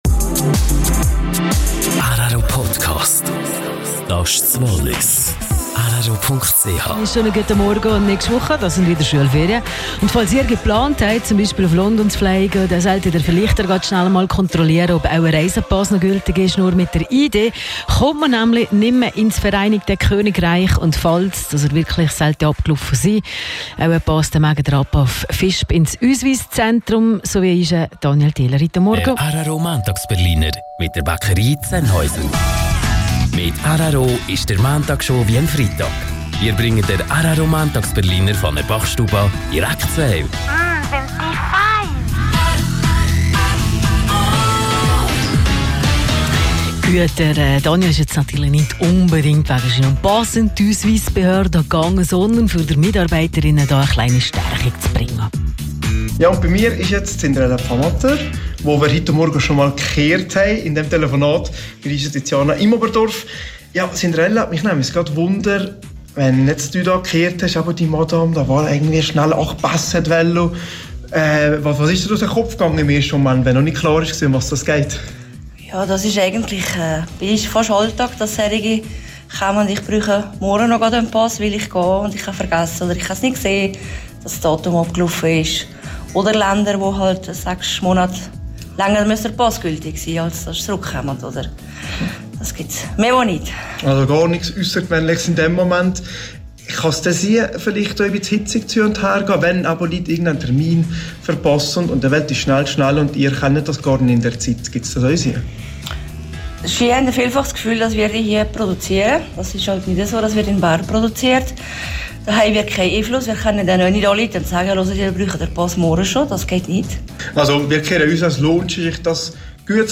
Nachfolgend die beiden Radiobeiträge, beim ersten Anruf und dann bei der Übergabe (auf Walliserdeutsch):